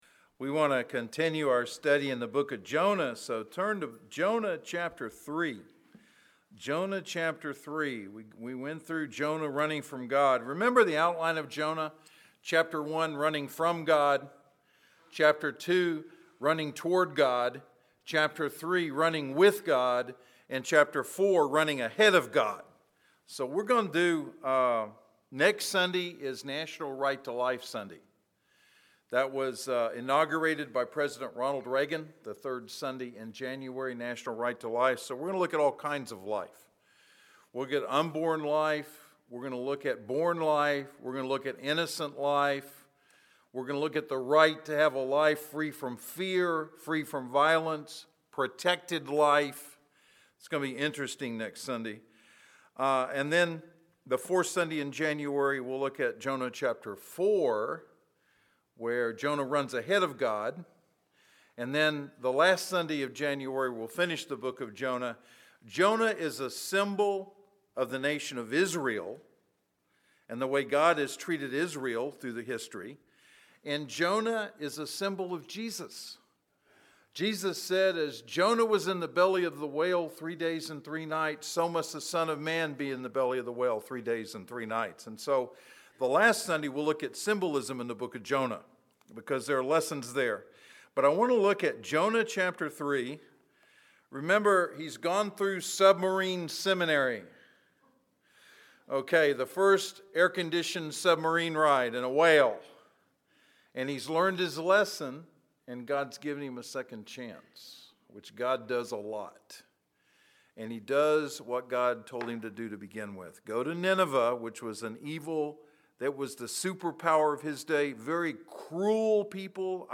Inman Park Baptist Church SERMONS